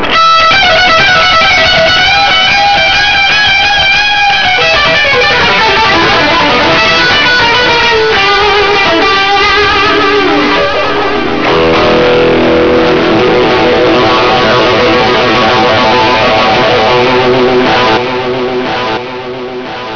NyteFlyte was a jazz band I helped form while I was stationed on the USS Constellation.
A lead solo I did on my guitar.
Live recordings of "NyteFlyte"
JAM2.WAV